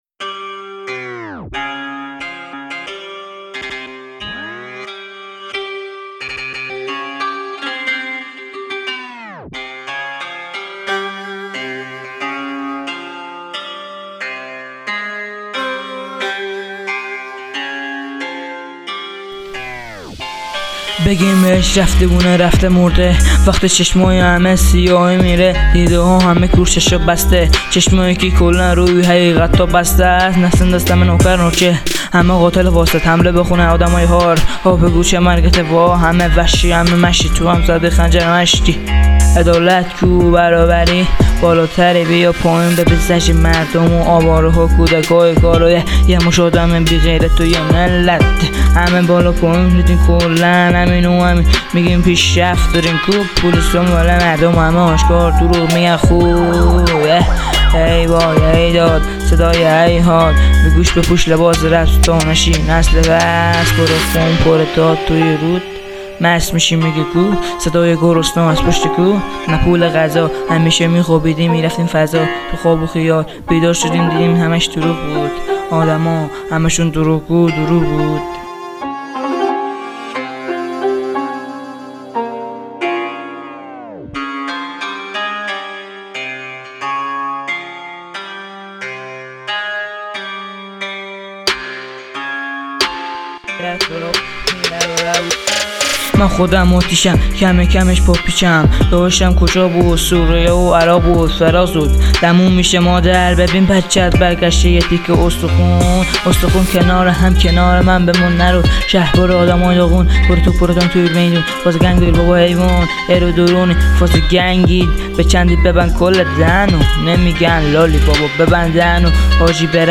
موزیک
rap